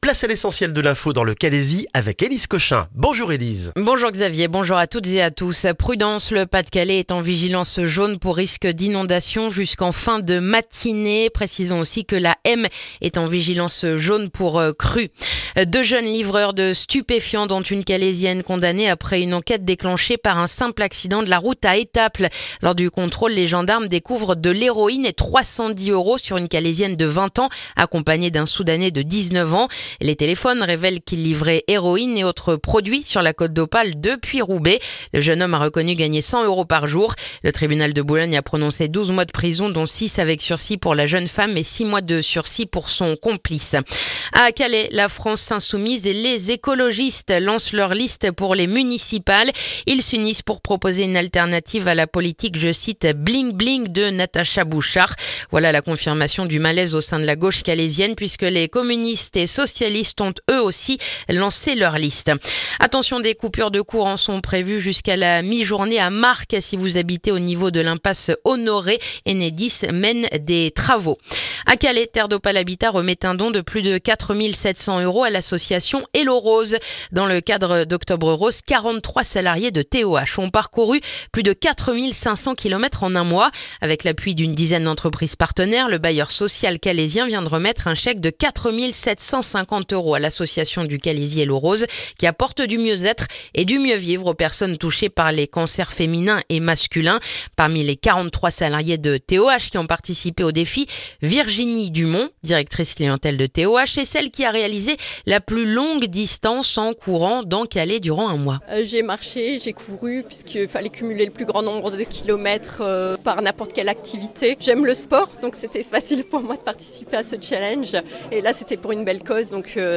Le journal du mardi 25 novembre dans le calaisis